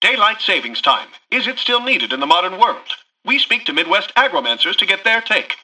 Newscaster_headline_42.mp3